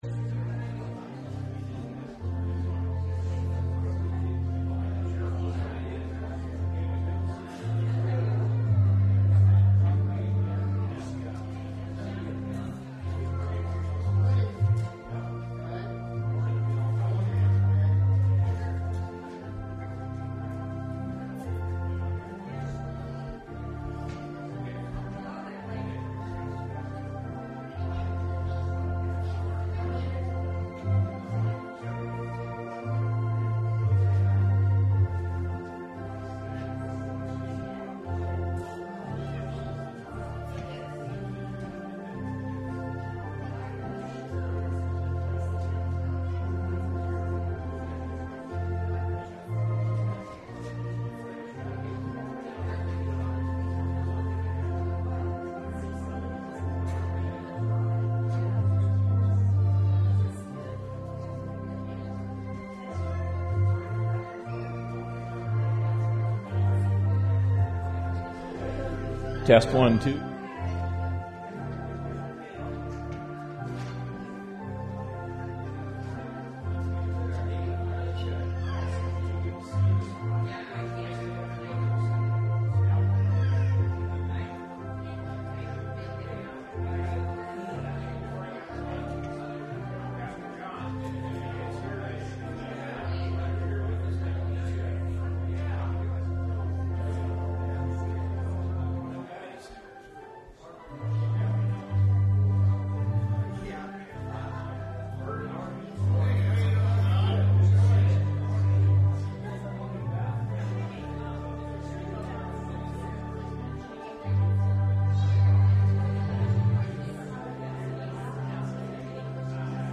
Service Type: Sunday Morning Service Topics: Easter , Israel , Life of Jesus , Resurrection Day